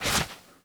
melee_draw_temp2.wav